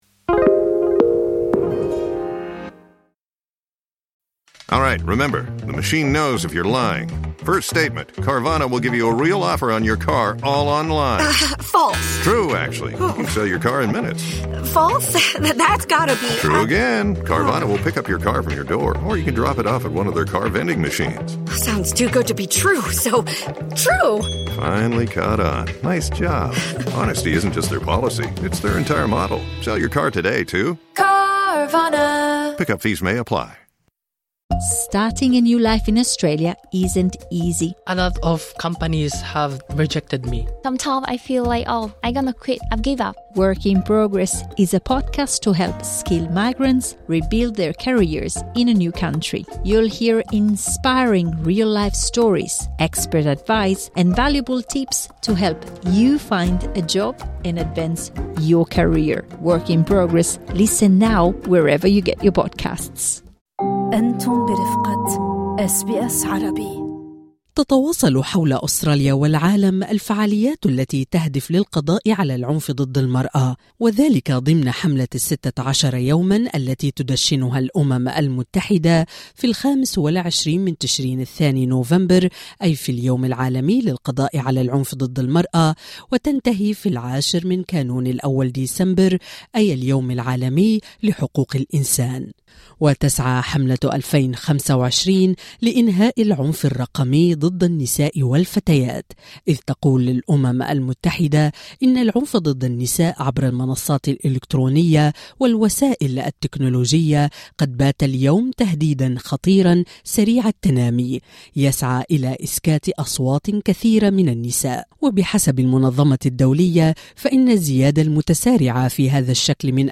مختصة تقنية تجيب